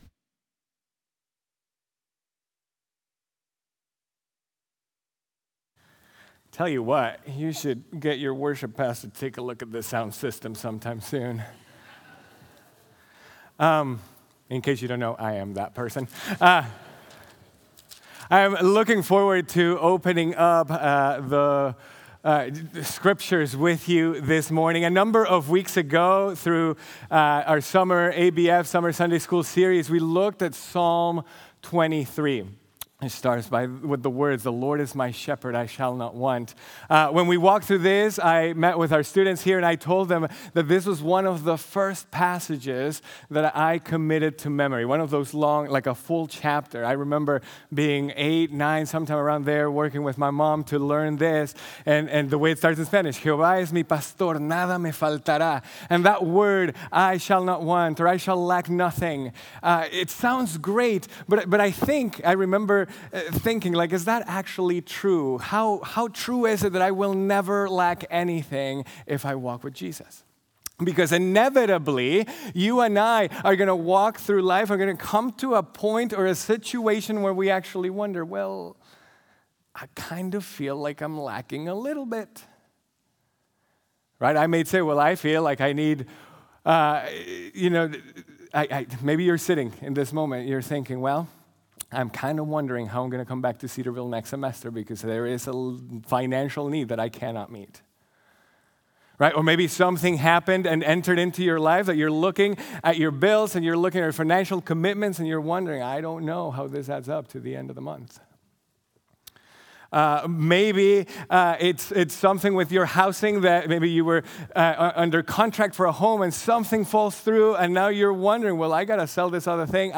The Lord Will Provide | Baptist Church in Jamestown, Ohio, dedicated to a spirit of unity, prayer, and spiritual growth